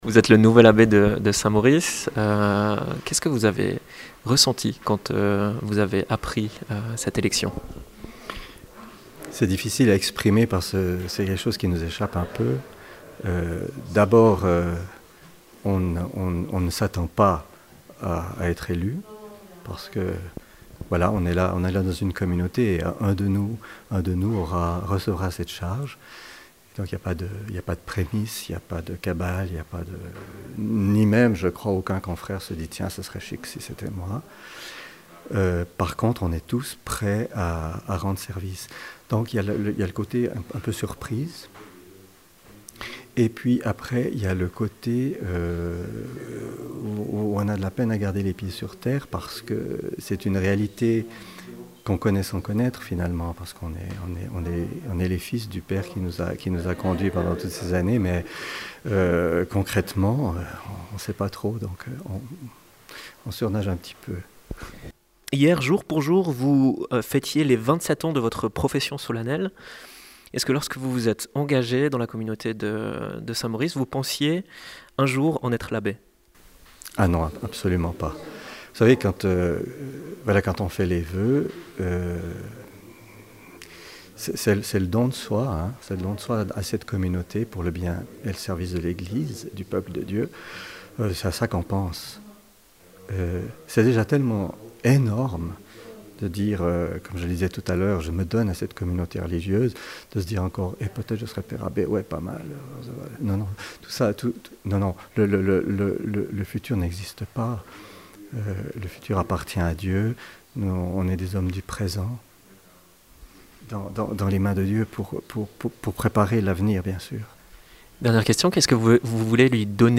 Mgr Jean Scarcella s’est présenté à la presse, ce vendredi 22 mai en fin de matinée.
Interview de Jean Scarcella, nouvel Abbé: